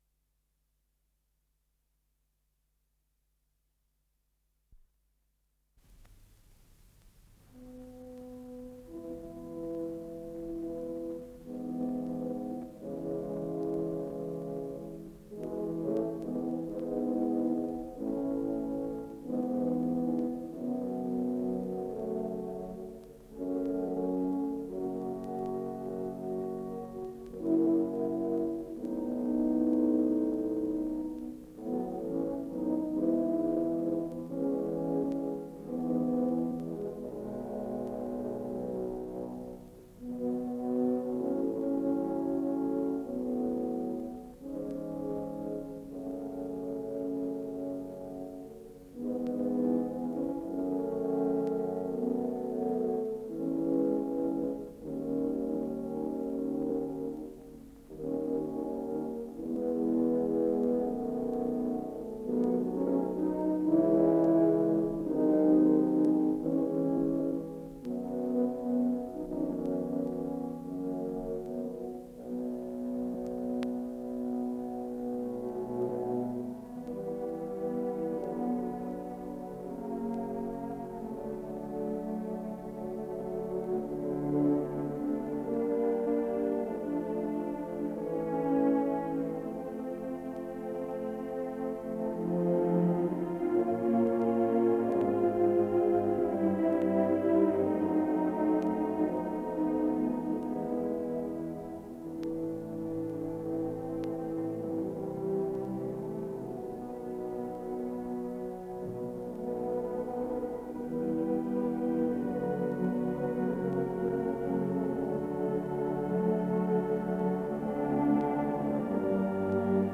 Исполнитель: Амстердамский оркестр
Название передачи Увертюра к опере "Тангейзер" Код ПВ-092640 Фонд Норильская студия телевидения (ГДРЗ) Редакция Музыкальная Общее звучание 00:14:20 Дата записи 1993 Дата переписи 18.04.1995 Дата добавления 28.08.2022 Прослушать